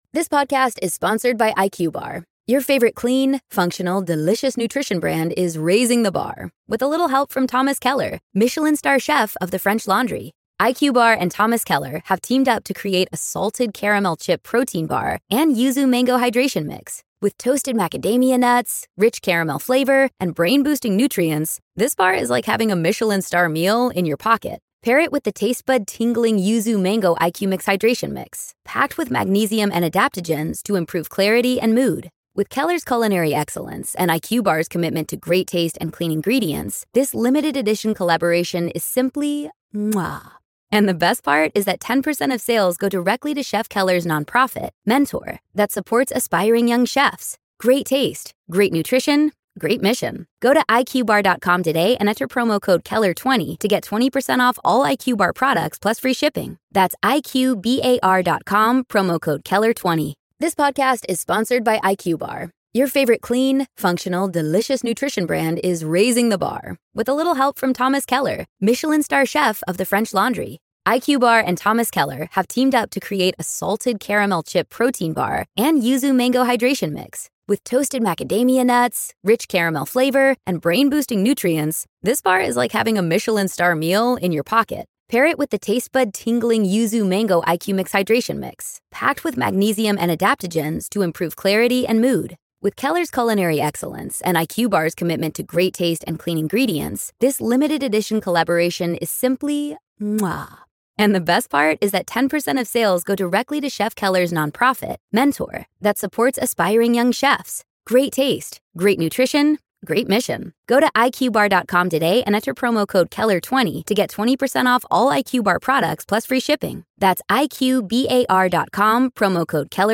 This is of course the audio-only edition of On-Screen Live , if you want the full experience, check out the show on our YouTube channel.